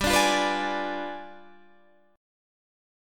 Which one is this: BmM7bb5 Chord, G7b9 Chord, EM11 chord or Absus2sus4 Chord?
G7b9 Chord